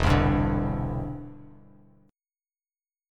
Em7#5 chord